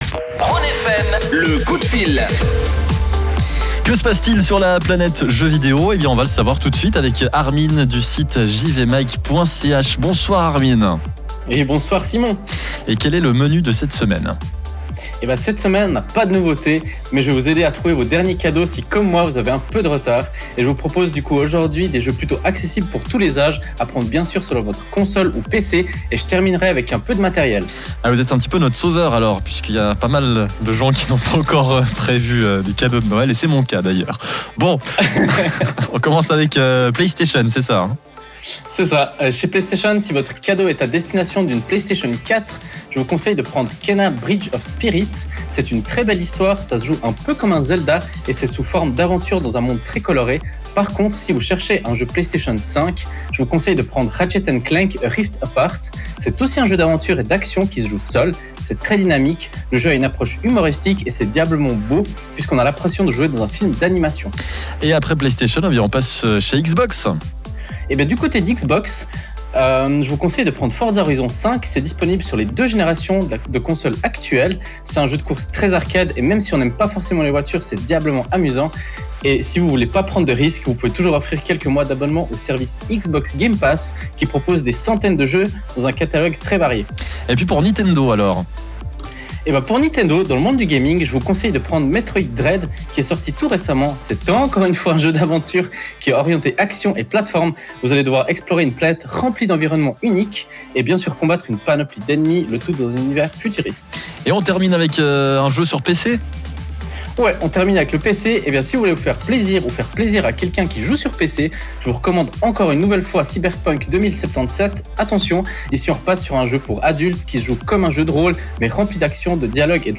Pour notre 17ième chronique sur la radio Rhône FM, je ne vous propose pas de nouveautés, mais plutôt des idées cadeaux si vous avez pris du retard pour Noël. Voici donc quelques jeux à prendre pour faire plaisir à vos proches, ou vous faire plaisir à vous même.